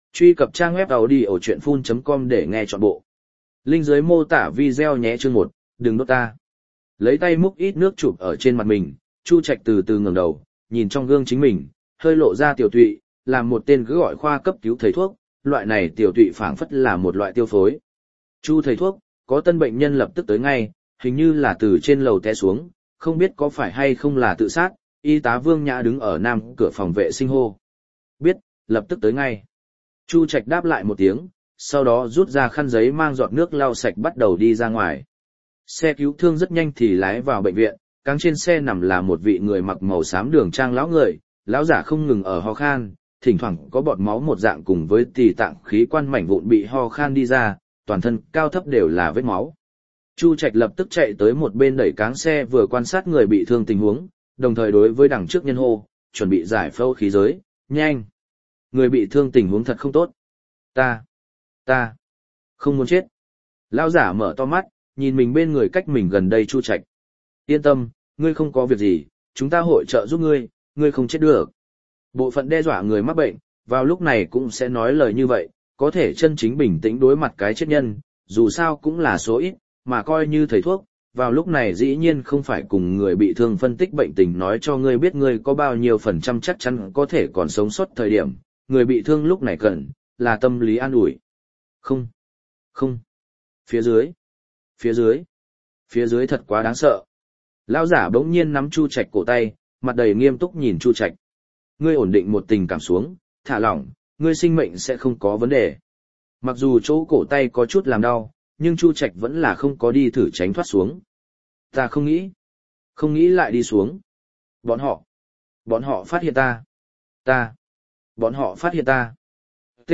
Thâm Dạ Thư Ốc Audio - Nghe đọc Truyện Audio Online Hay Trên TH AUDIO TRUYỆN FULL